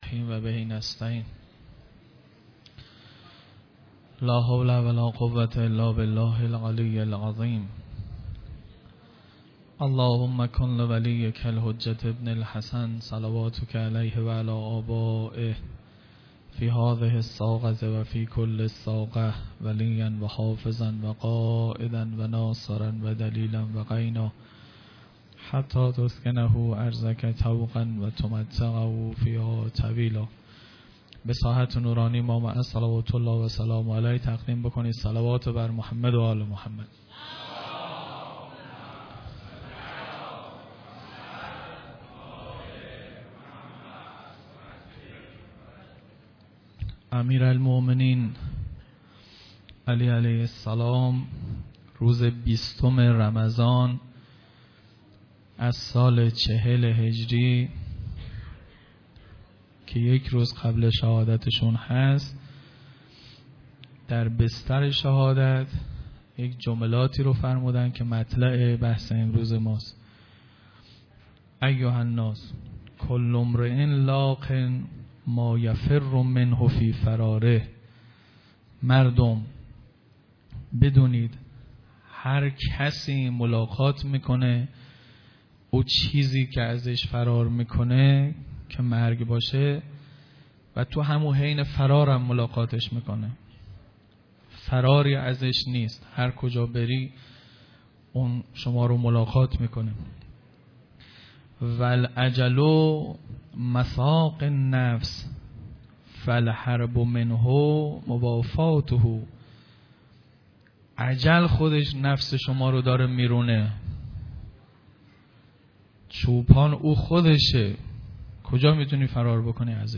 سخنرانیهای